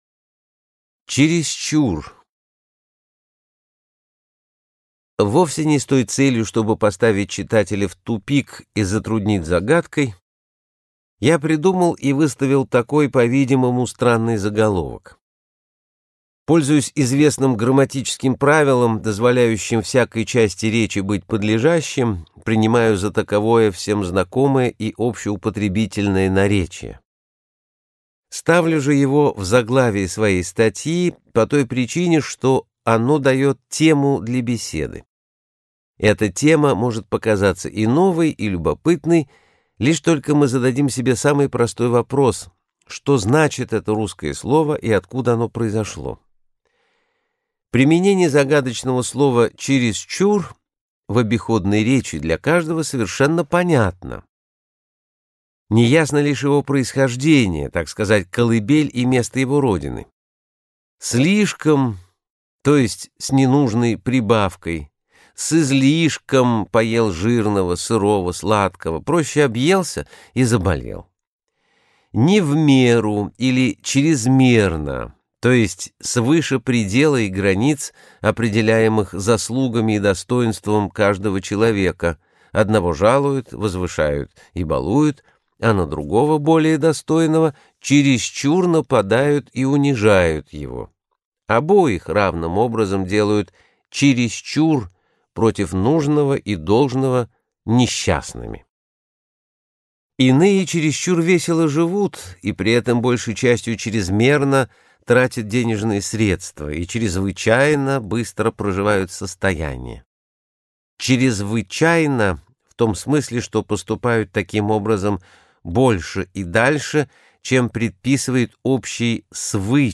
Аудиокнига Крылатые слова и выражения, притчи, байки, поверия русского народа | Библиотека аудиокниг